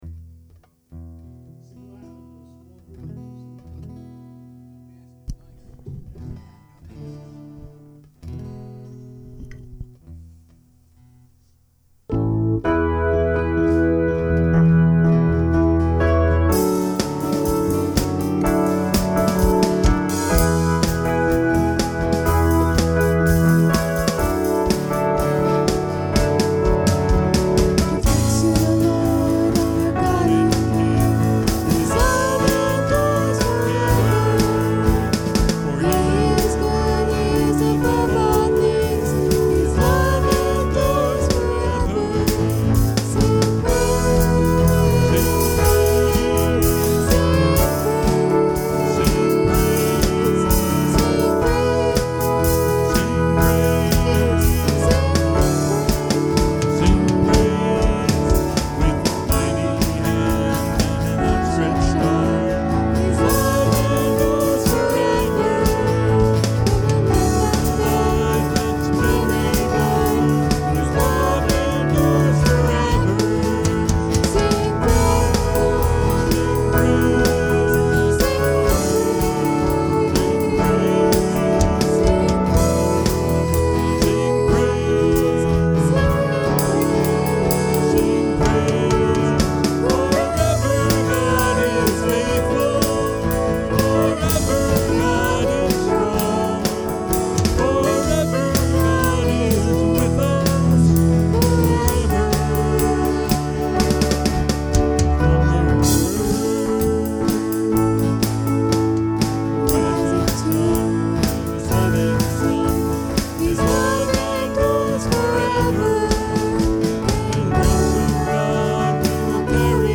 Acts 20:17-38 Service Type: Sunday Morning Worship Intro